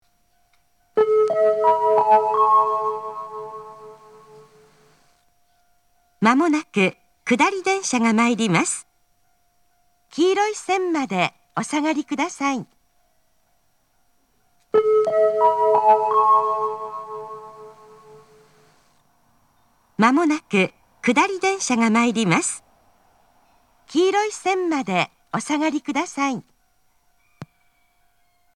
仙石旧型（女性）
接近放送
仙石旧型女性の接近放送です。同じ内容を2度繰り返します。
Kagawa-DSekkin.mp3